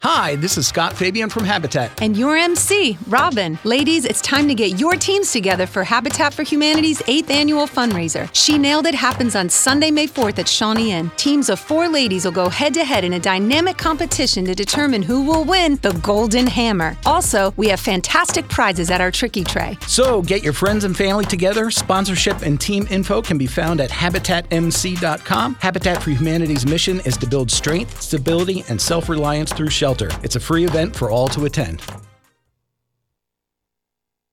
On the Radio: POCO 103, 96.7-97.3 Bigfoot Country & 93.5 SBG – She Nailed It! 2025 Commercial